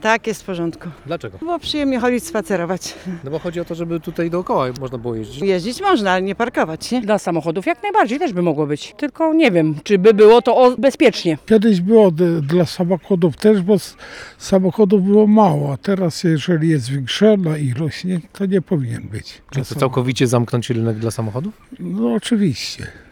Mieszkańcy już zabrali głos
Co na to mieszkańcy Kościerzyny?